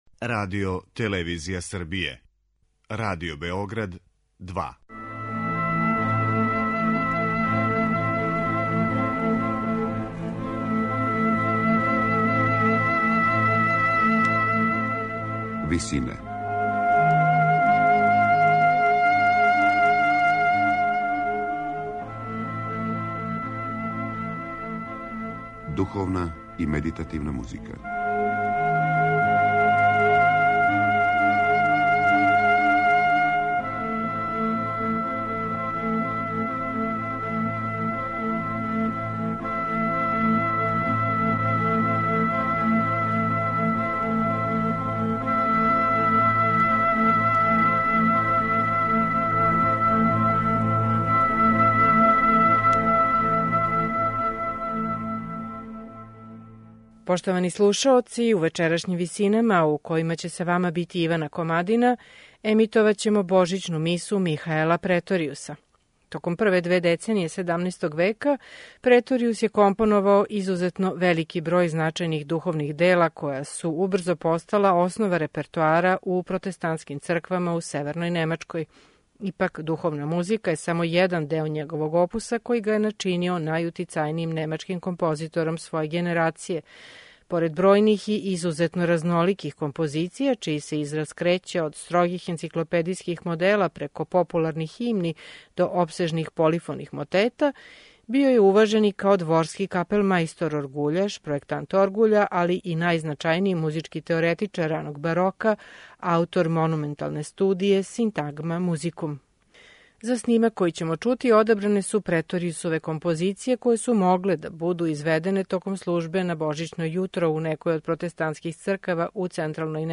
Духовна музика
У вечерашњим Висинама чућемо Преторијусове композиције које су могле да буду изведене током службе на божићно јутро у некој од протестанских цркава у централној Немачкој око 1620. године. Извођачи су: ансамбл Gabrieli Consort & Players, Хор дечака и Конгрегацијски хор катедрале у Роскилу, под управом Пола Мек Криша.